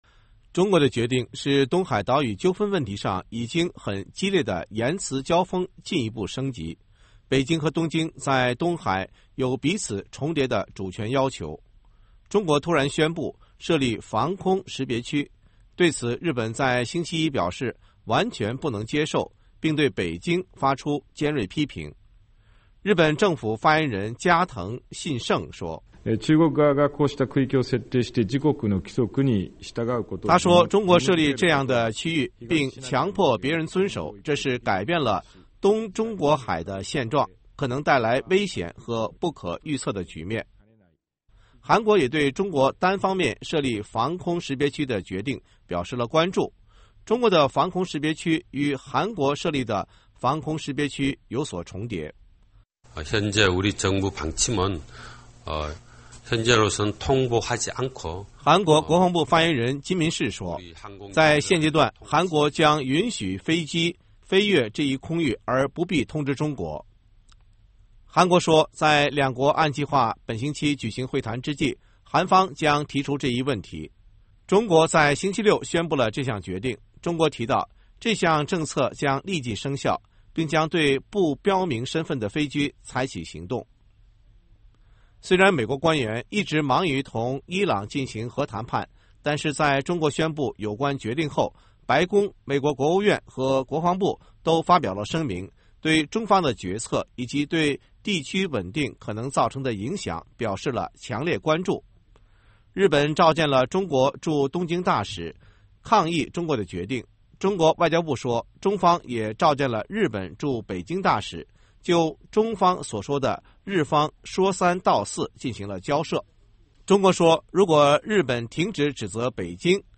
音频报道:日韩拒绝接受中国在争议海域划设防空识别区